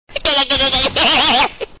Goofy Laugh Sound Effect Free Download
Goofy Laugh